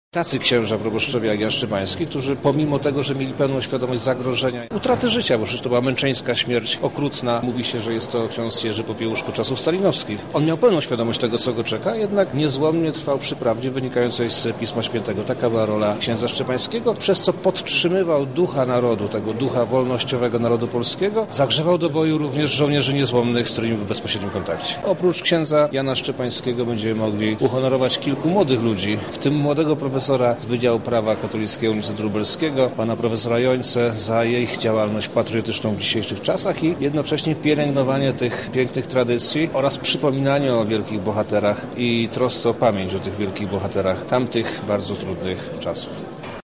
Zmarłego duchownego wspomina również Wojewoda Lubelski Przemysław Czarnek, dodaje on także kto jeszcze został odznaczony.